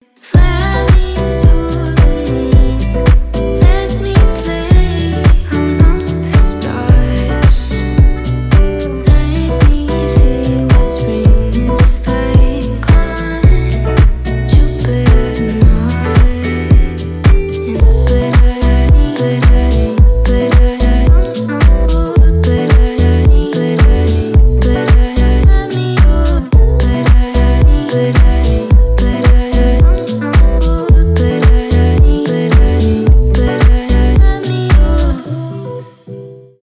chill house
романтические